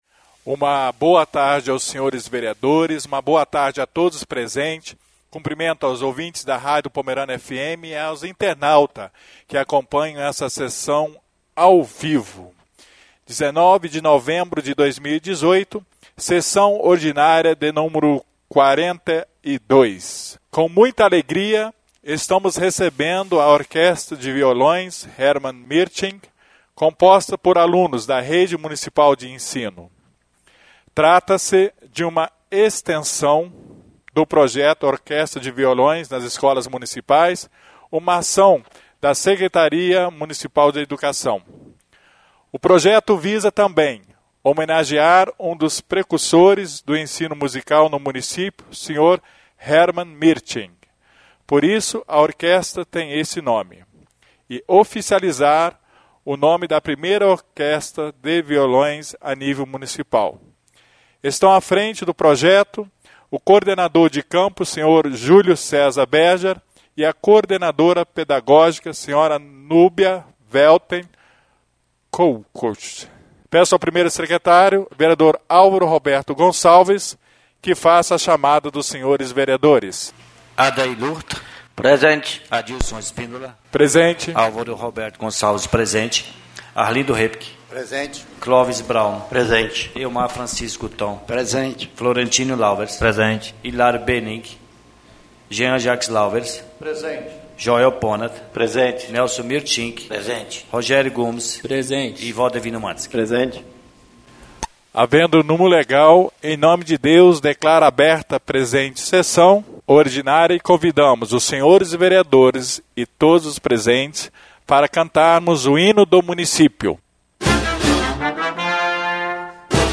SESSÃO ORDINÁRIA Nº 42/2018